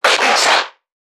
NPC_Creatures_Vocalisations_Infected [97].wav